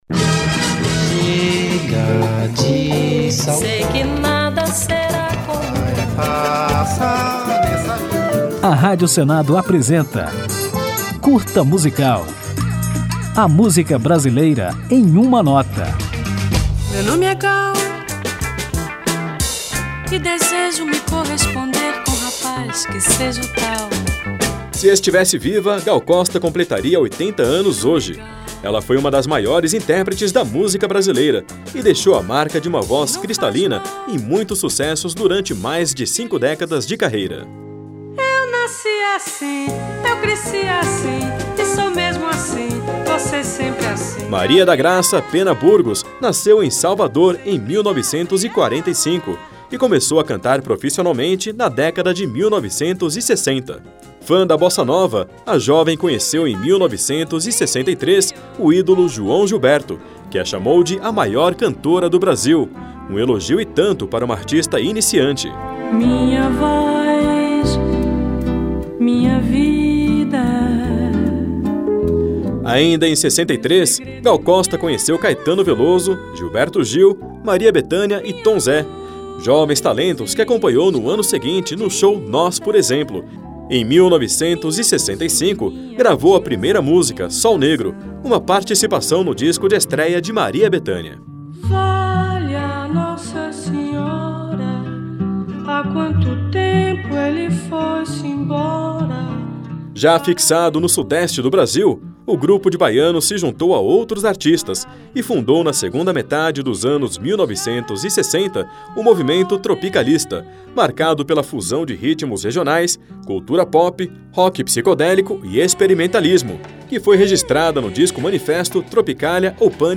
Aperte o play para conhecer a trajetória da cantora, que começou nas experimentações do movimento tropicalista, passou por anos de sucessos radiofônicos e se consolidou como ídolo de antigas e novas gerações. Ao final do programete, você vai ouvir Chuva de Prata, um dos grandes sucessos de Gal Costa.